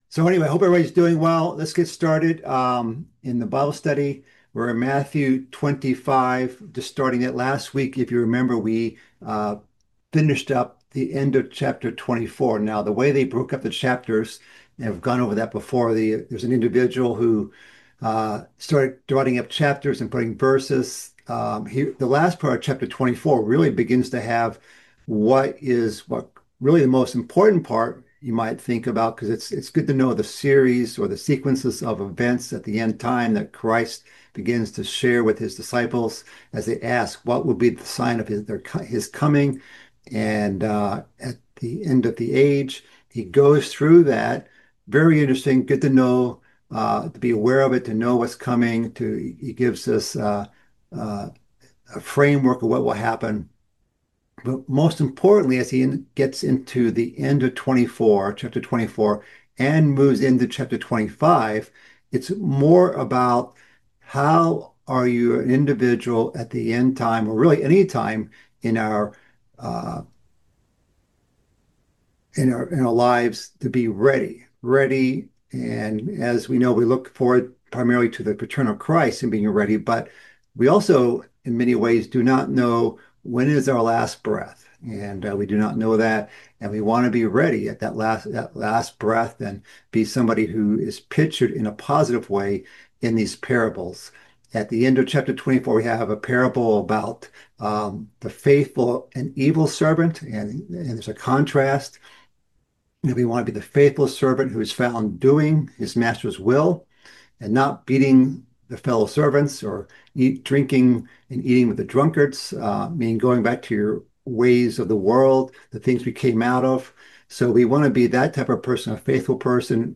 This is the eleventh part of a mid-week Bible study series covering Christ's fifth discourse in the book of Matthew.